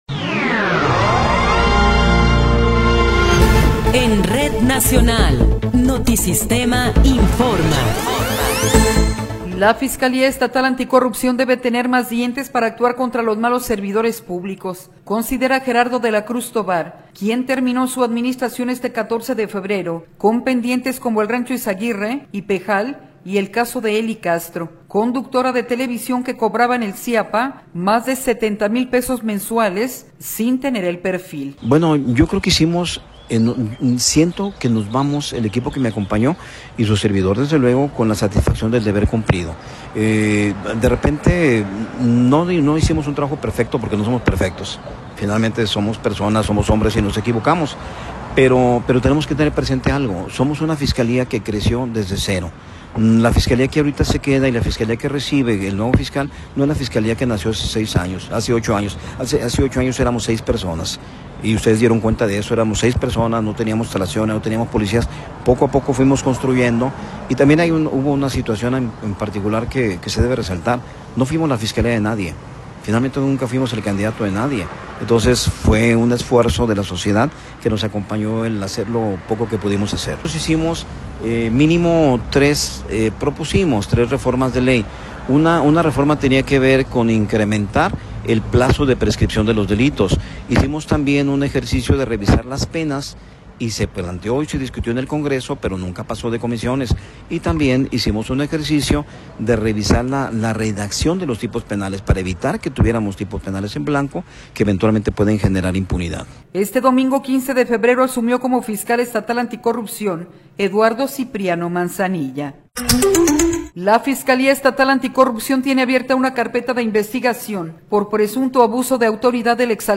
Noticiero 21 hrs. – 15 de Febrero de 2026
Resumen informativo Notisistema, la mejor y más completa información cada hora en la hora.